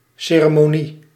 Ääntäminen
IPA : /ˈsɛrəmoʊnɪ/